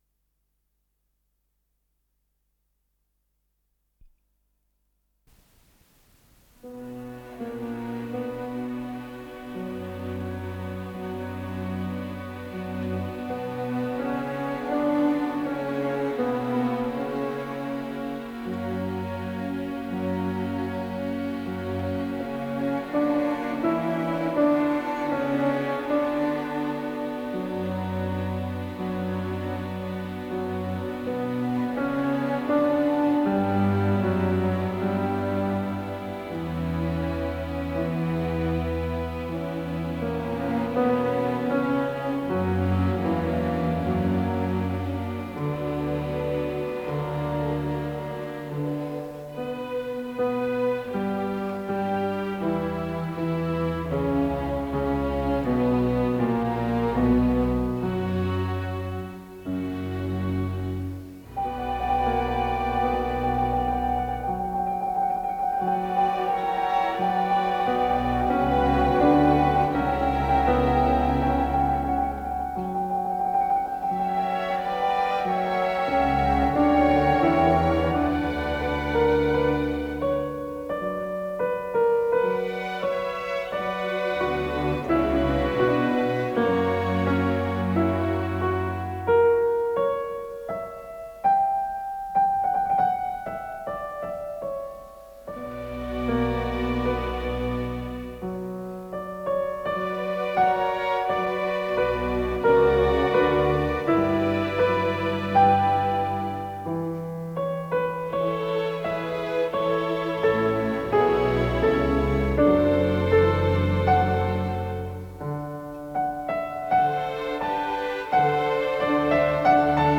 ИсполнителиАндрей Гаврилов - фортепиано
АккомпаниментКамерный оркестр